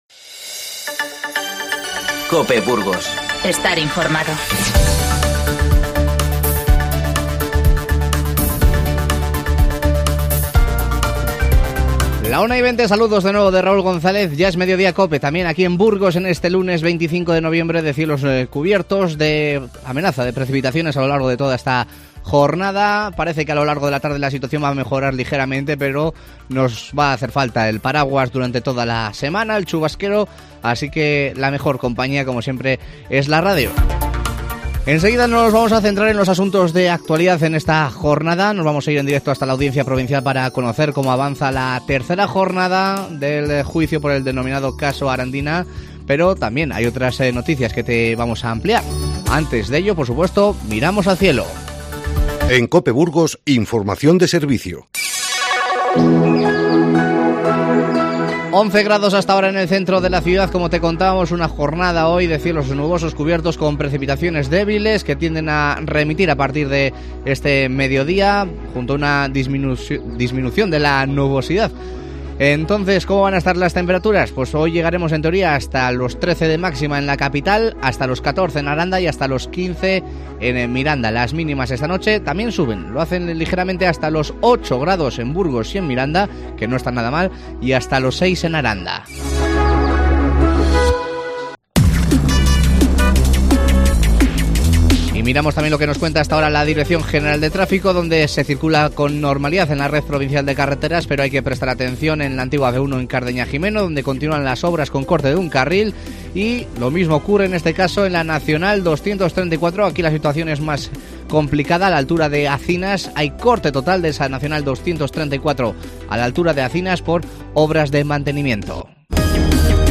Conectamos en directo con la Audiencia Provincial para seguir la tercera jornada del juicio por el 'caso Arandina' y repasamos otros asuntos de actualidad.